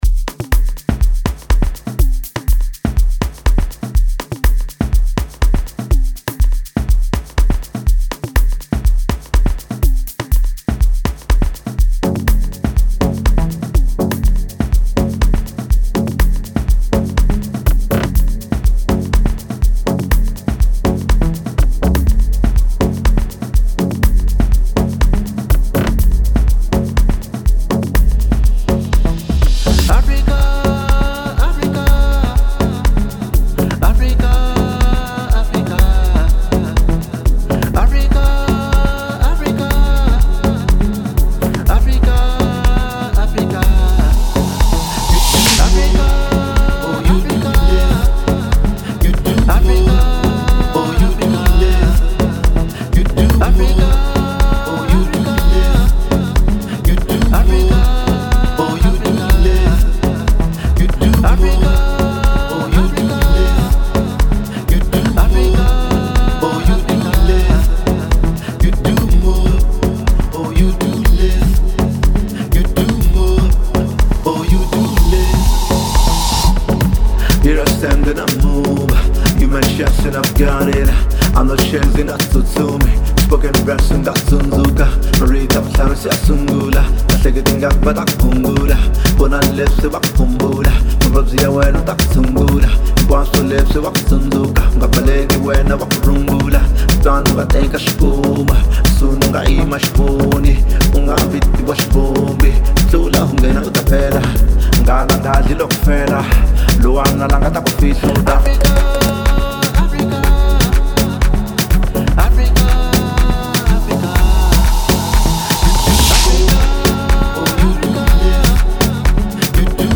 06:00 Genre : House Size